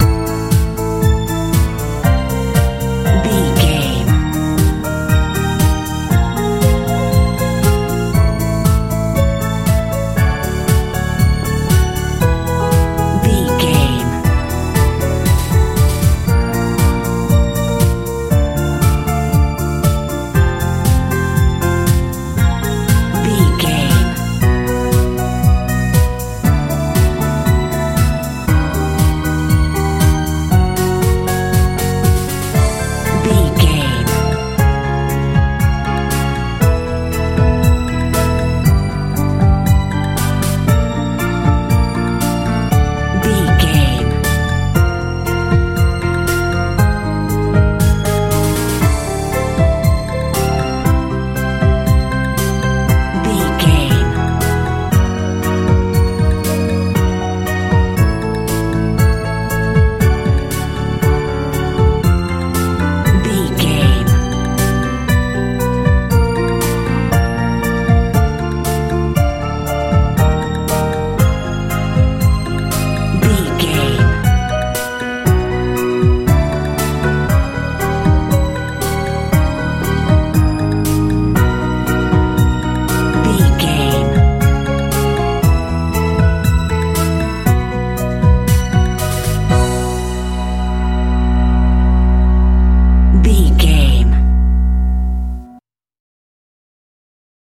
modern pop fell
Ionian/Major
cool
synthesiser
bass guitar
drums
80s
90s
hopeful